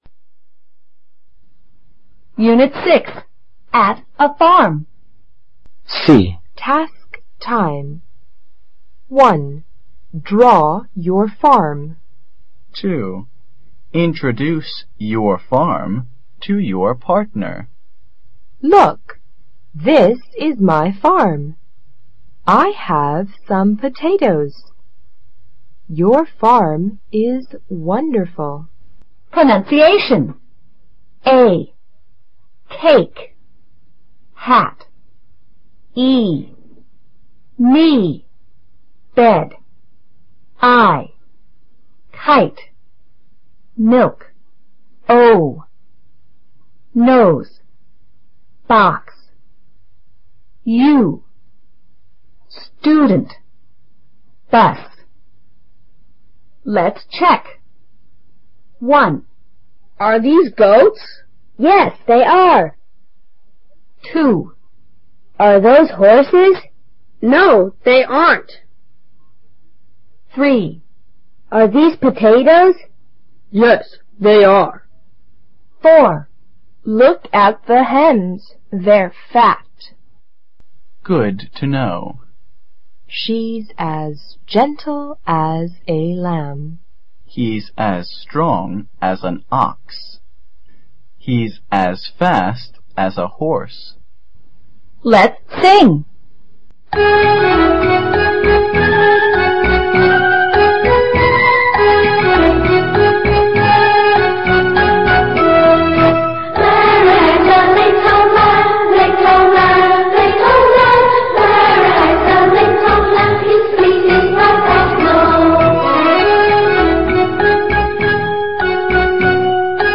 2012年PEP人教版小学英语四年级下册f_unit6c 课文听力MP3+LRC